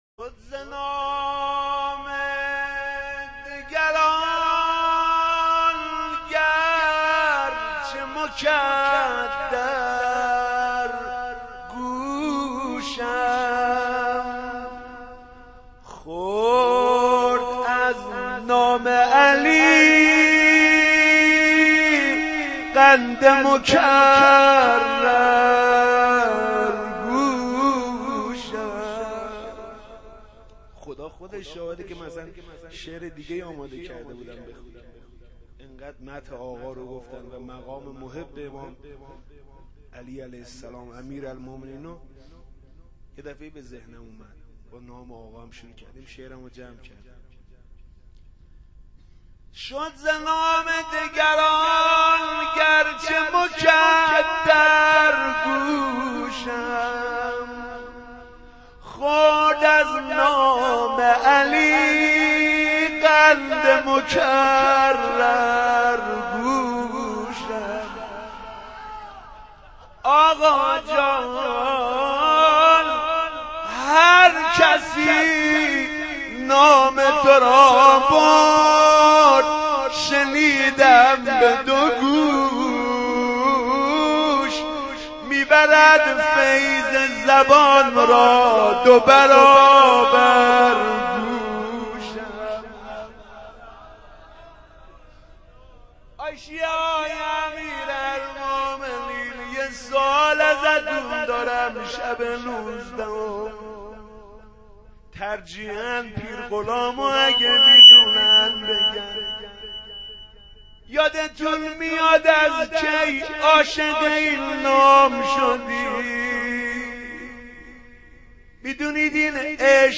روضه.wma